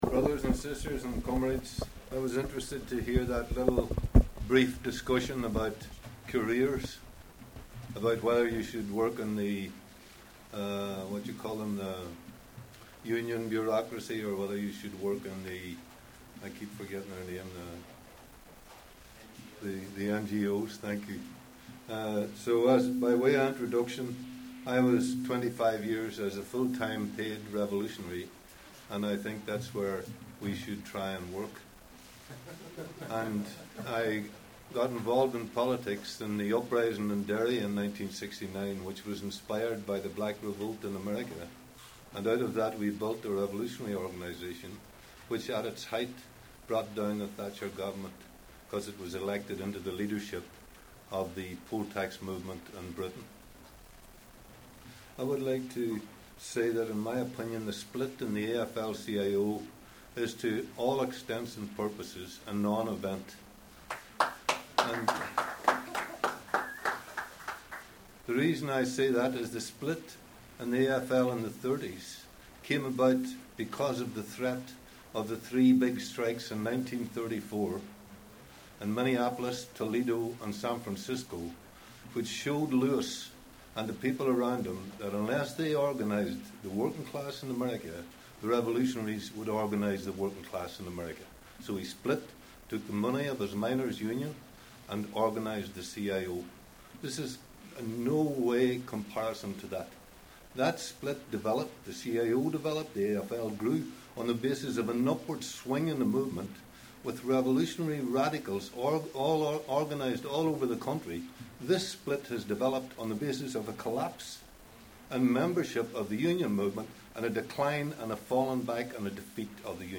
Beyond the Split- three more speeches